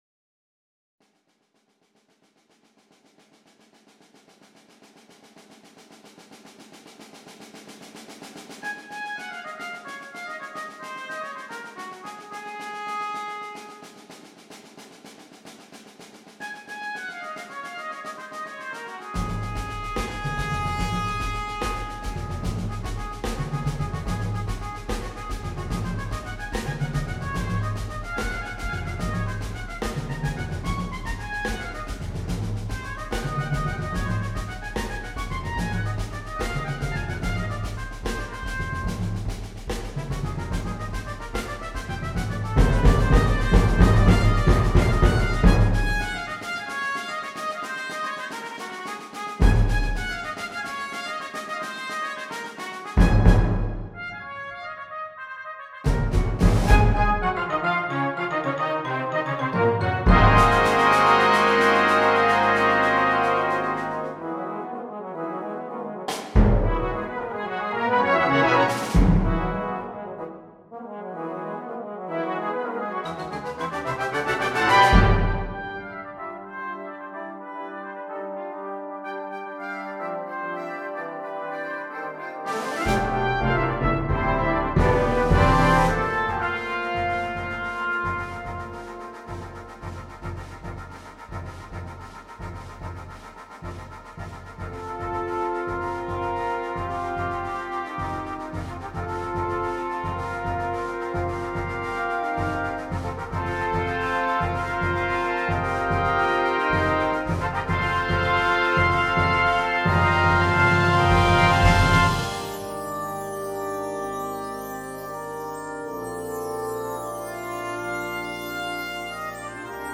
Besetzung: Soprano Cornet or Eb Trumpet Solo & Brass Band